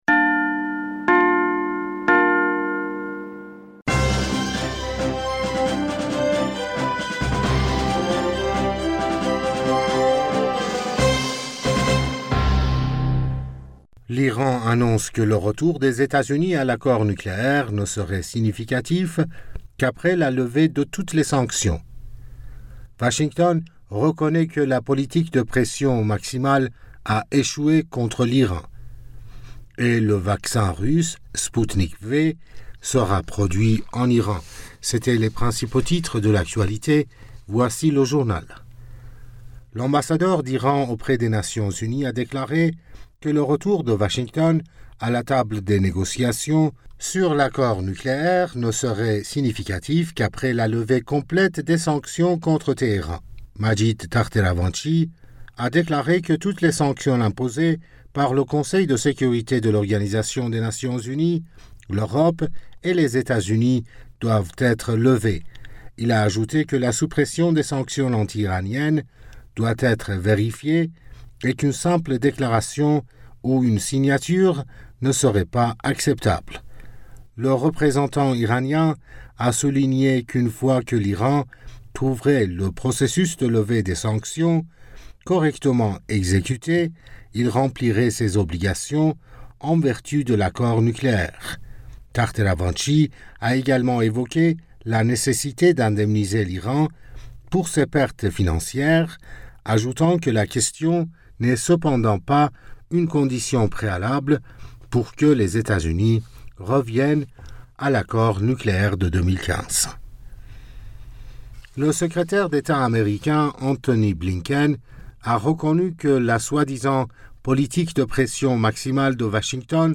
Bulletin d'informationd u 20 Février 2021